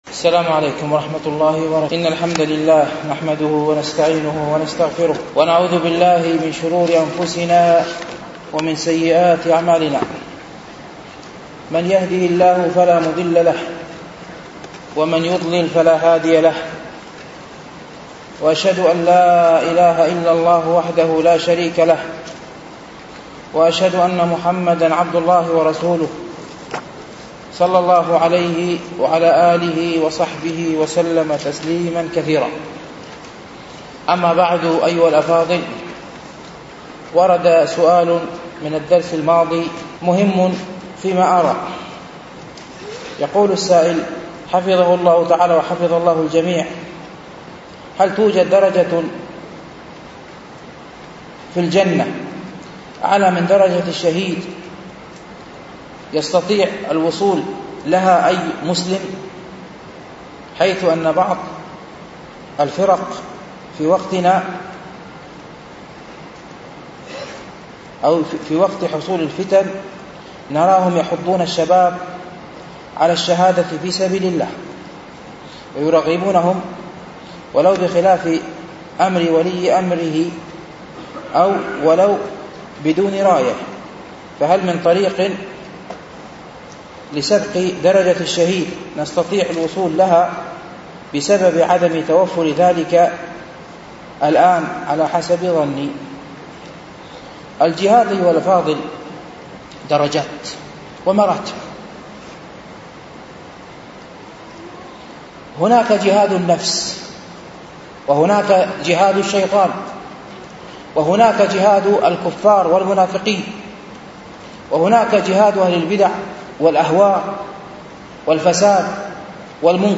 شرح رياض الصالحين ـ الدرس الحادي والثمانون